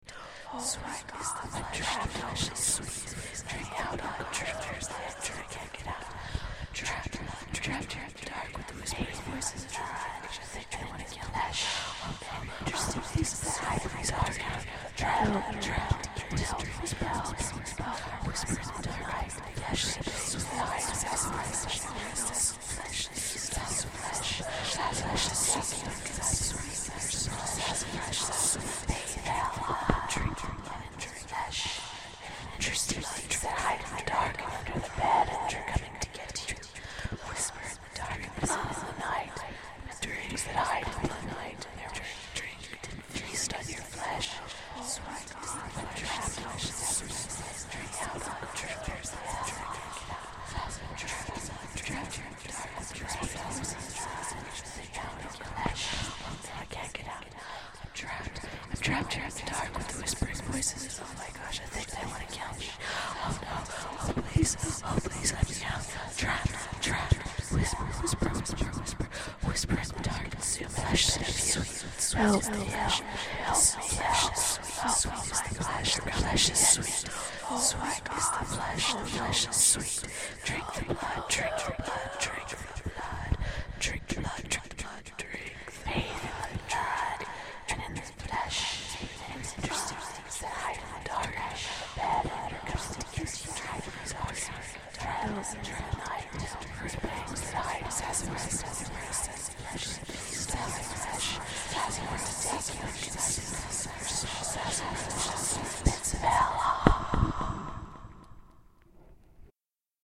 Звук, как темные силы шепчутся в темноте (голоса из потустороннего мира)
zvuk-kak-temnye-sily-shepchutsia-v-temnote-golosa-iz-potustoronnego-mira.mp3